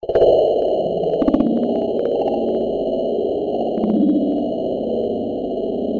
科幻无人机 " 无人机08
描述：科幻无人机，用于室内或室外房间的音调，气氛，外星人的声音，恐怖/期待的场景.
Tag: 外来 大气压 无人驾驶飞机 音响 恐怖 roomtones SCI